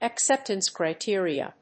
acceptance+criteria.mp3